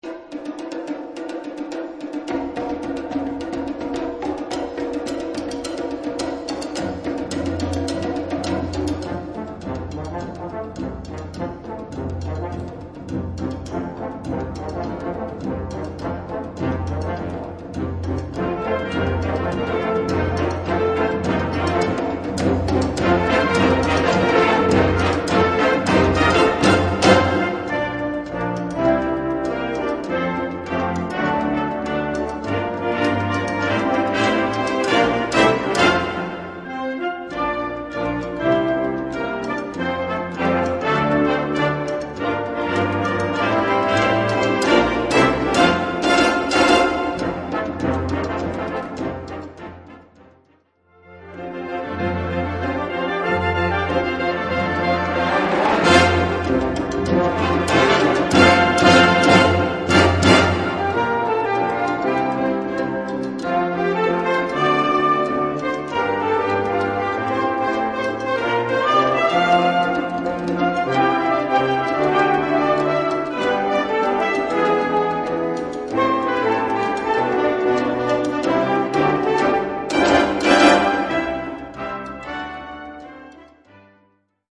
Besetzung: Blasorchester
"Latin Groove"